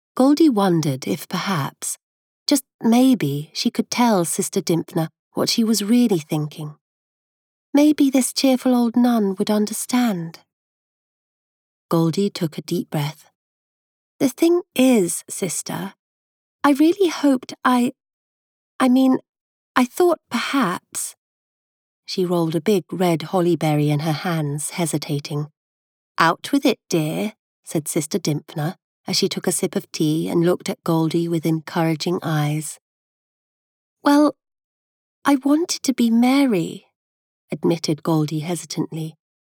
Voice Over Services British Narration and Commercial Voice Work
From children’s fiction to epic fantasy or memoirs, I provide engaging narration with pacing, characterisation, and warmth tailored to your audience.